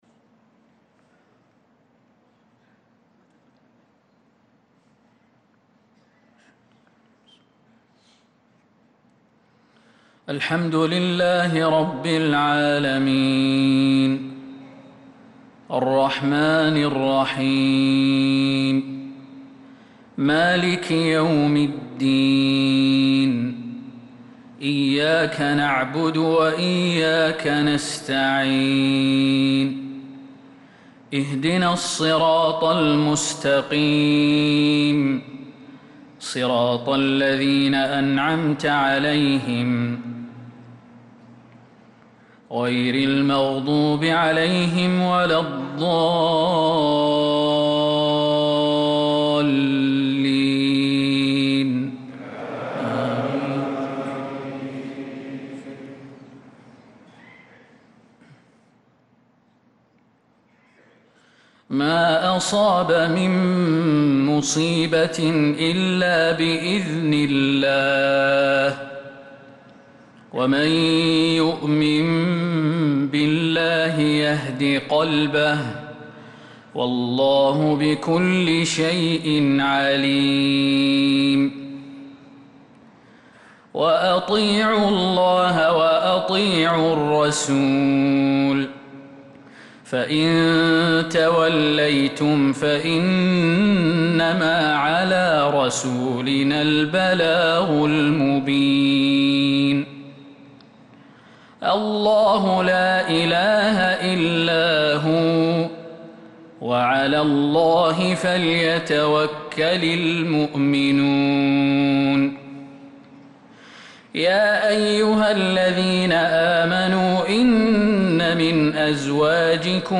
صلاة المغرب للقارئ خالد المهنا 16 ذو القعدة 1445 هـ
تِلَاوَات الْحَرَمَيْن .